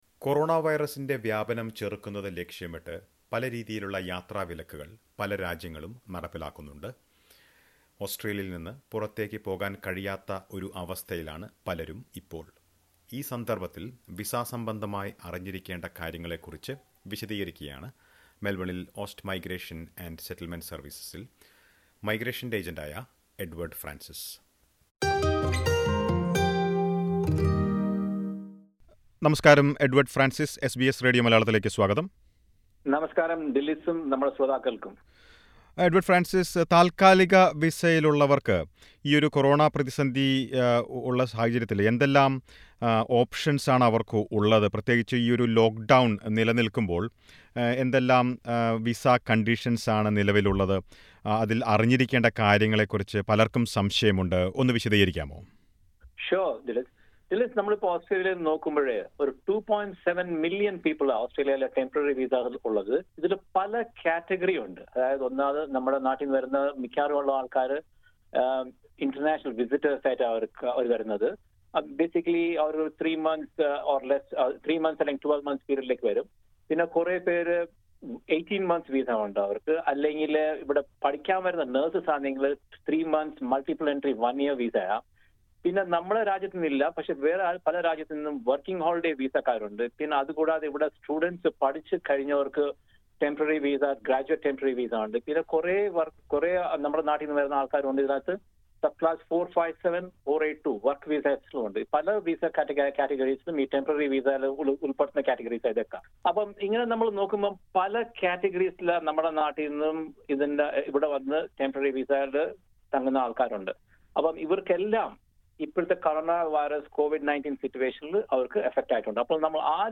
ഈ അഭിമുഖത്തിൽ ഉൾപെടുത്തിയിരിക്കുന്ന വിവരങ്ങൾക്ക് പുറമെ കോവിഡ് 19 പാൻഡെമിക്ക് എന്ന പേരിൽ സബ്ക്ലാസ്സ് 408 വിസയിൽ പുതിയ മറ്റു കാര്യങ്ങളും ഉൾപ്പെടുത്തിയിട്ടുണ്ട്‌ ഇതിന്റെ വിശദാംശങ്ങൾ ഇവിടെ ലഭ്യമാണ്.